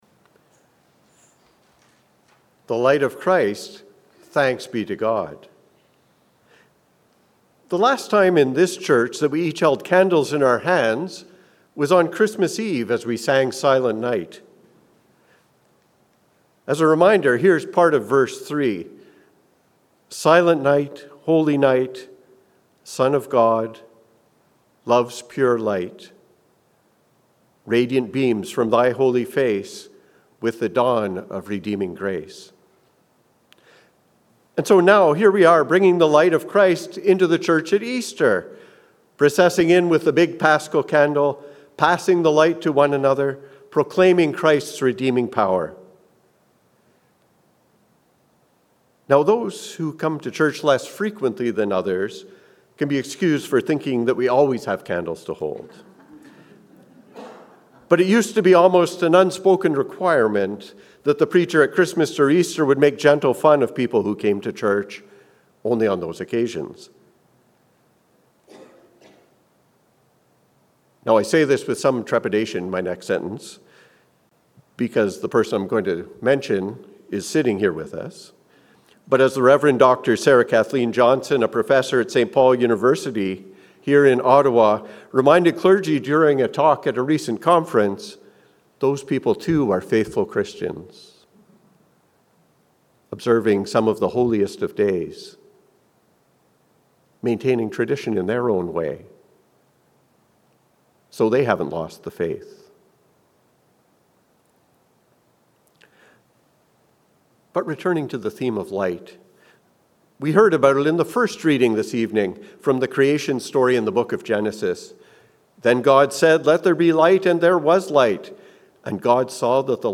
The Light of Christ. A sermon for the Great Easter Vigil